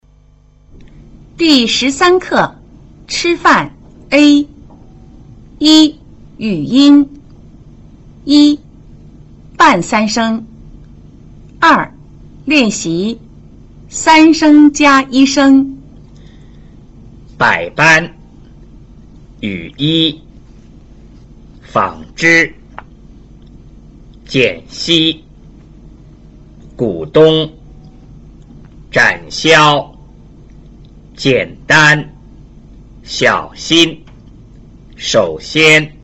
1. 半三聲
在語流中,第三聲詞往往讀成半三聲,也就是只讀音節聲調的降調部份。